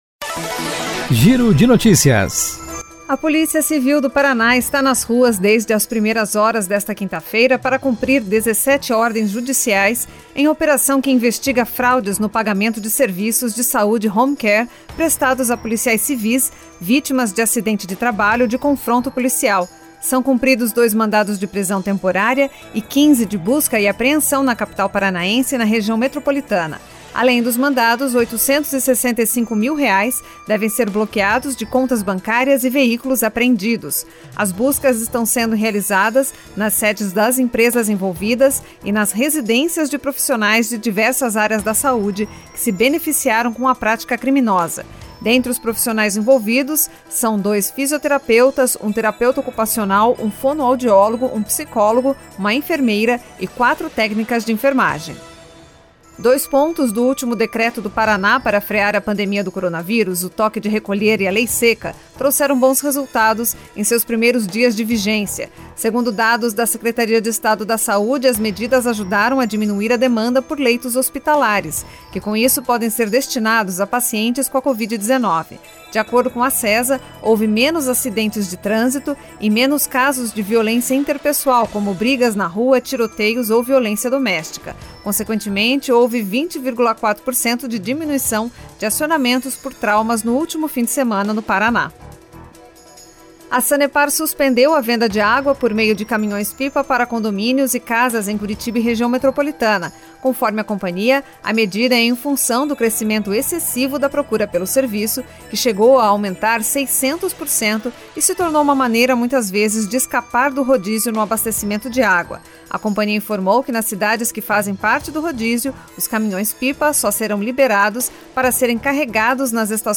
Giro de Notícias Manhã COM TRILHA